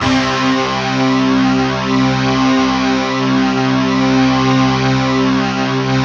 Index of /90_sSampleCDs/Optical Media International - Sonic Images Library/SI1_DistortGuitr/SI1_200 GTR`s